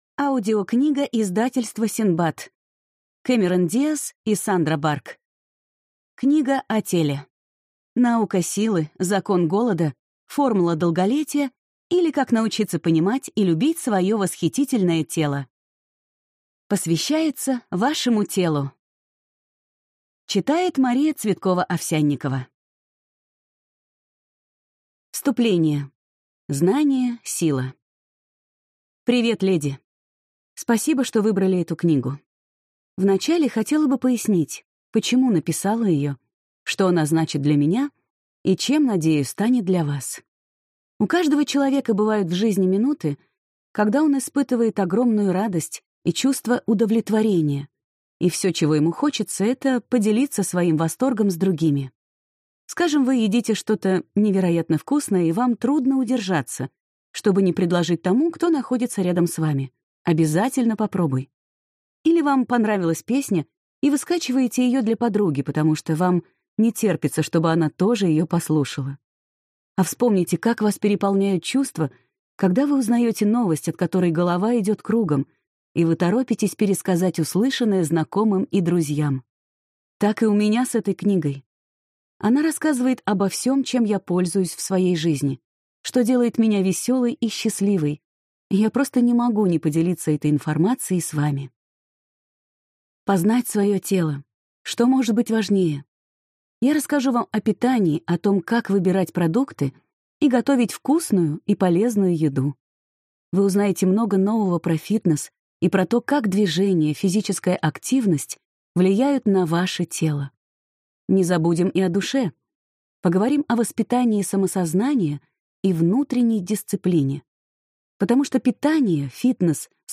Aудиокнига Книга о теле